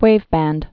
(wāvbănd)